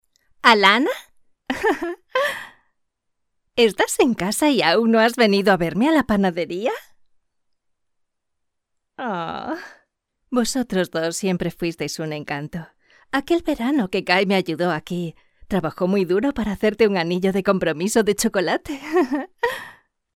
Actores y actrices de doblaje